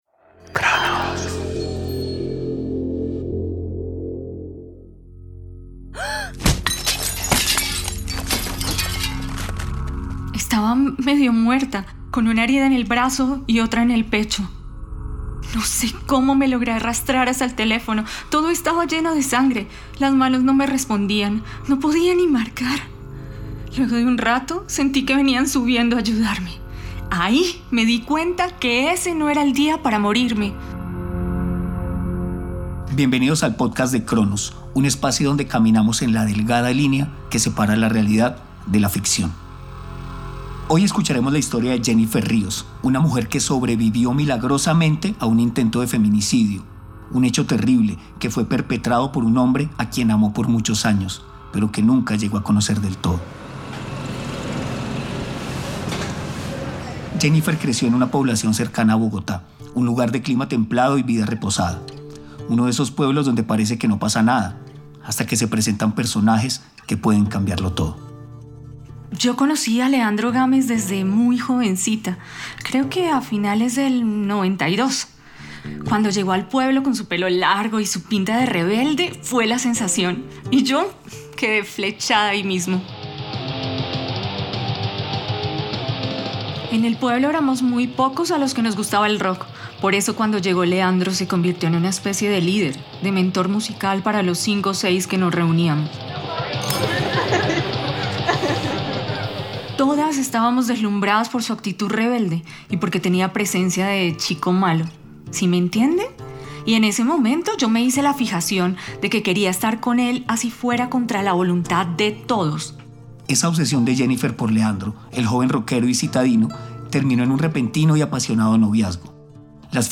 ..Pódcast. Una mujer explica cómo se inició en un grupo satánico de su barrio.